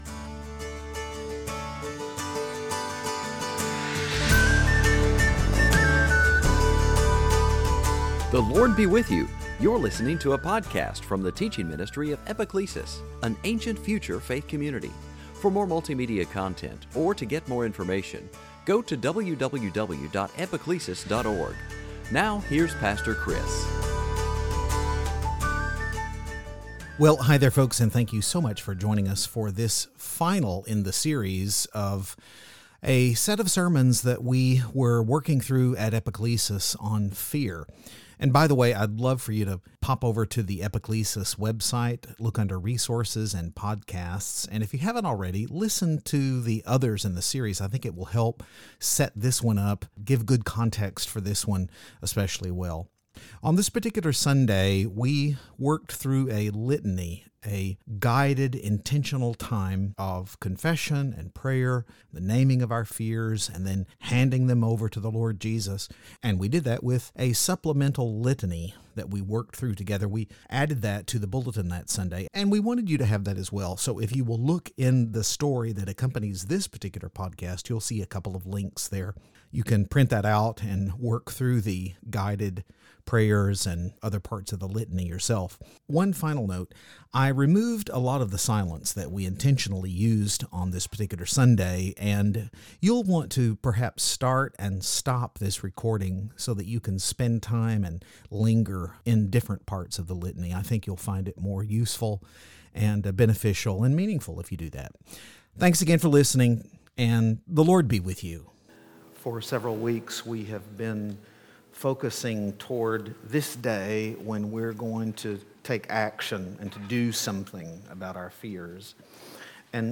The congregation had a special guided litany, a supplement to their morning worship liturgy, that we want to make available to you, too. Take some time to listen to the first three in the series of fear sermons, then use this podcast recording to confess and get rid of your own fears.